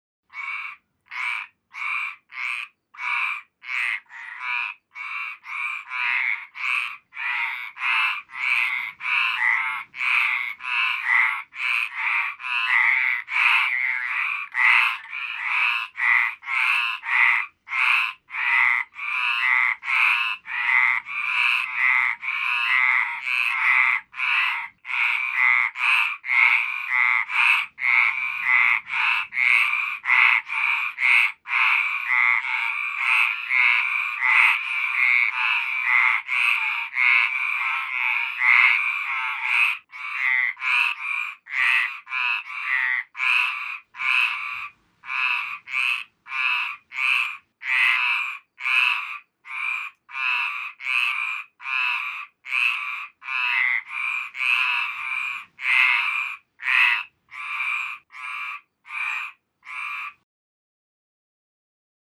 Royalty free sounds: Farm
mf_SE-6134-frog_3.mp3